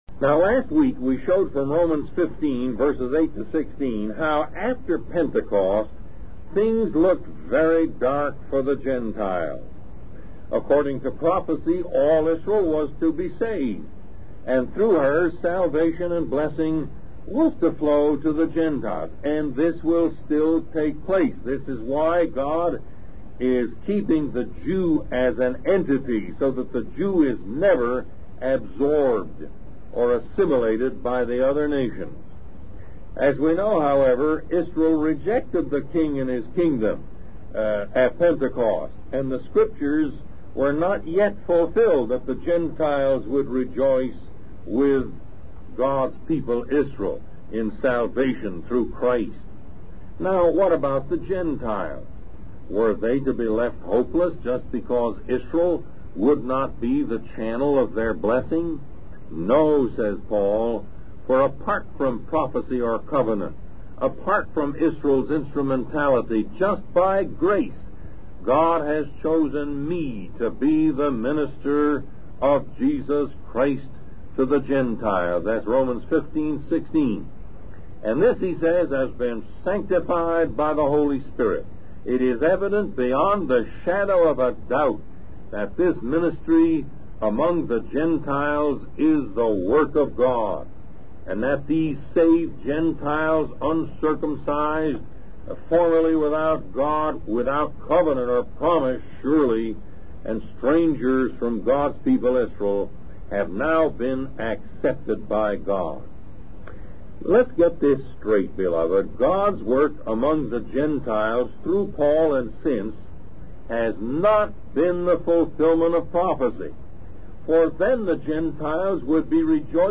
Lesson 66: Reaching the Masses for Christ